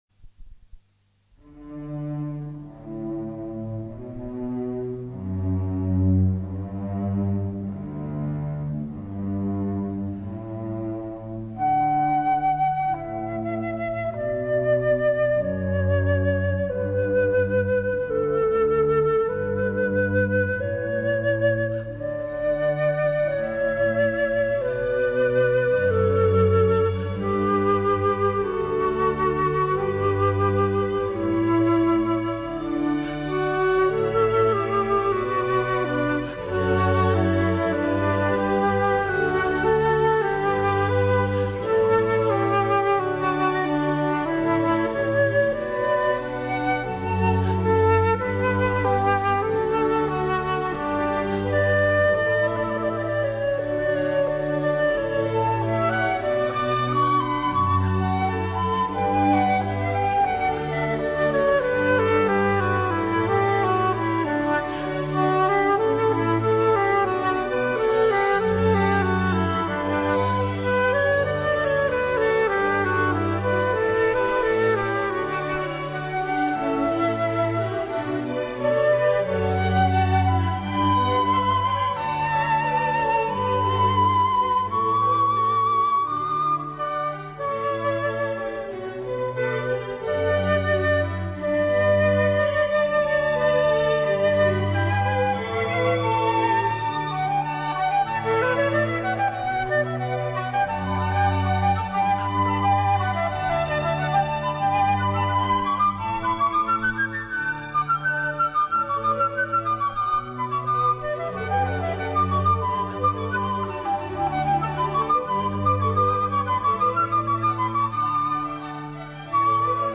所属分类： 音乐 >> 宗教/庆典音乐